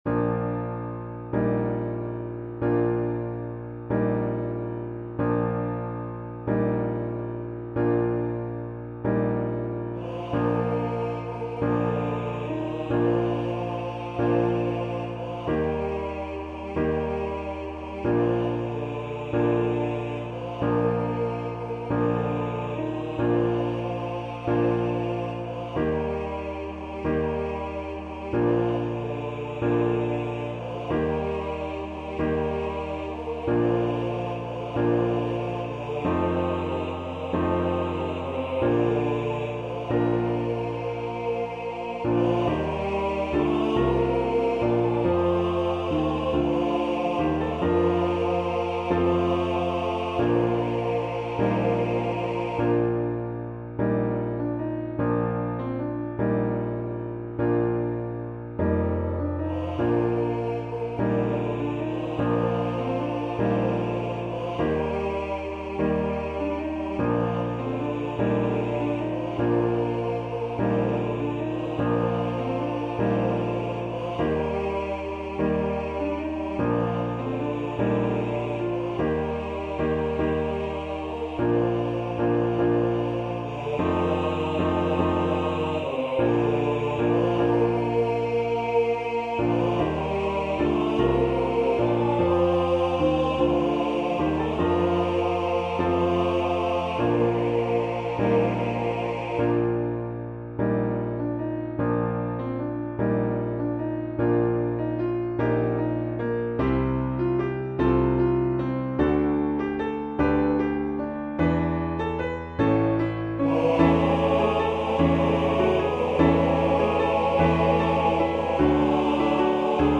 Voicing/Instrumentation: TBB , TTB